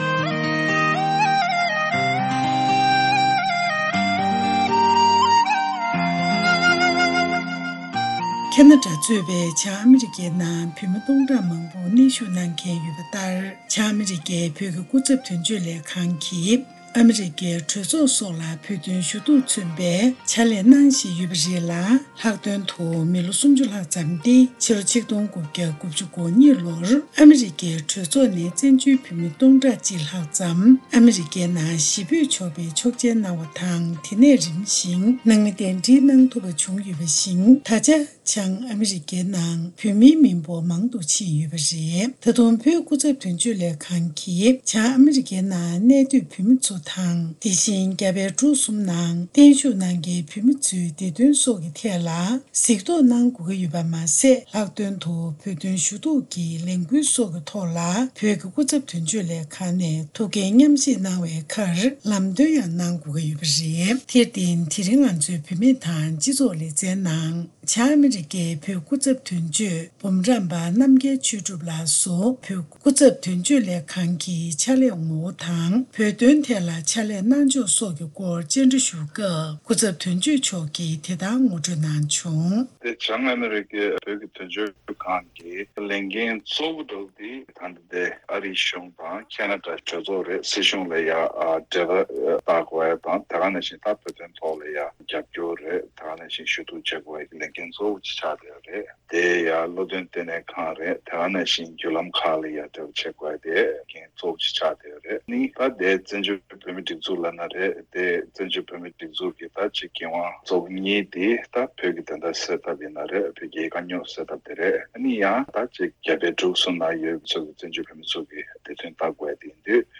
འབྲེལ་ཡོད་མི་སྣར་གནས་འདྲི་ཞུས་པ་ཞིག་གསན་རོགས་གནང།